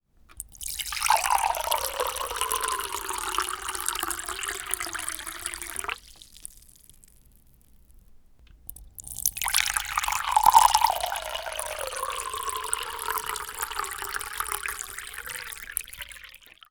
На этой странице собраны разнообразные звуки наливания воды и других жидкостей: от наполнения стакана до переливания напитков в высокий бокал.
Шум чая льющегося в кружку